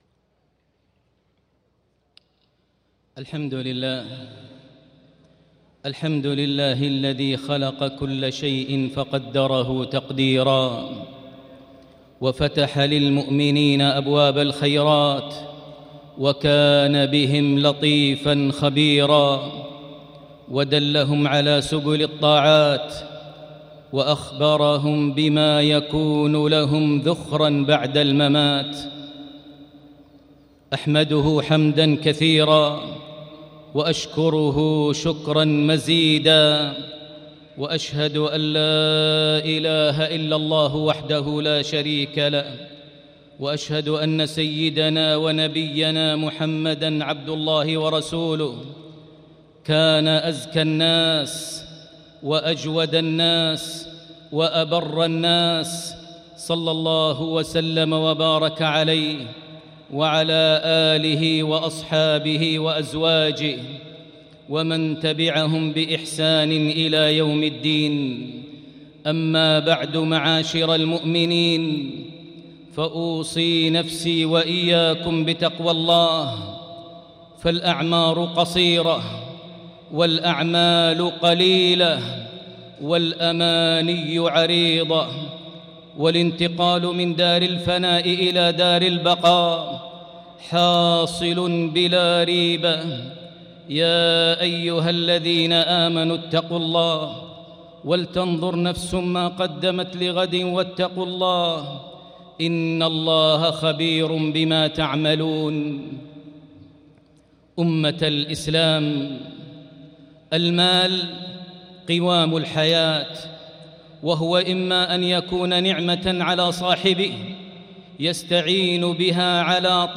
Khutbah Jumu’ah 1-3-2024 > KHUTB > Miscellanies - Maher Almuaiqly Recitations